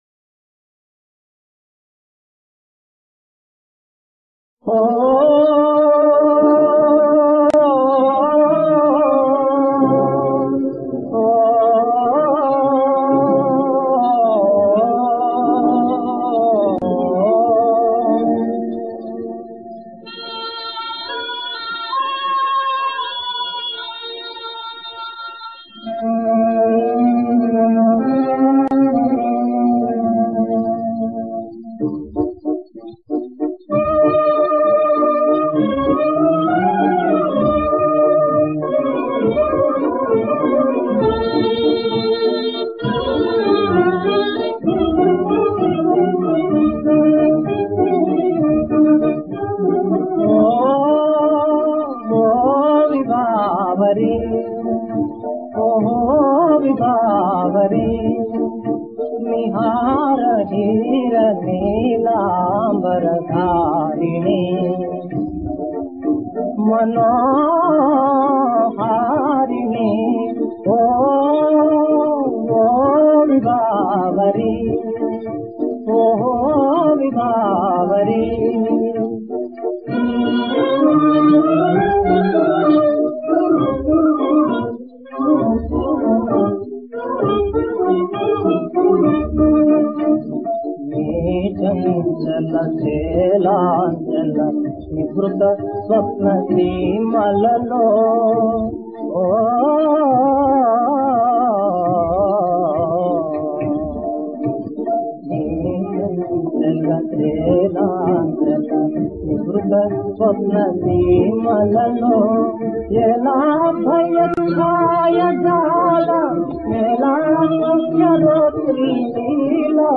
అలనాటి లలిత గీతం
మంచి క్వాలిటీతో..
టీవీలో వచ్చిన ఒక పాటలపోటీ